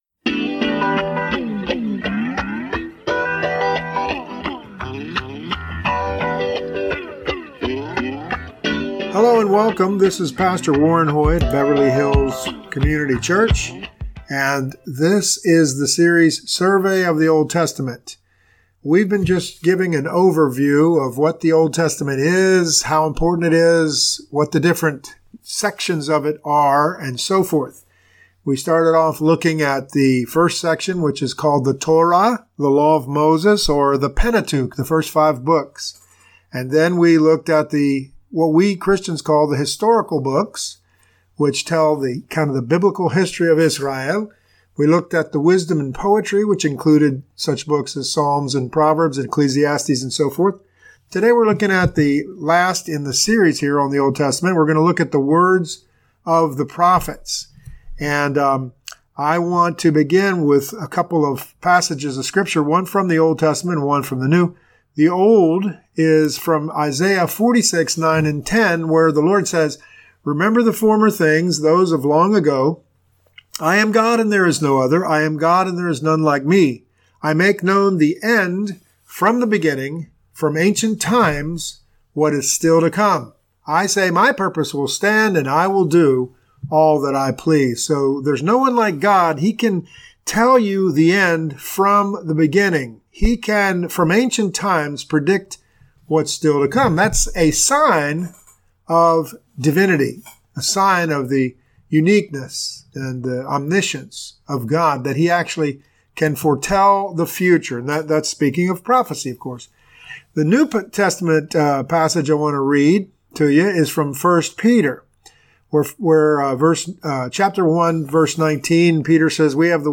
This teaching examines the fascinating section called the prophets.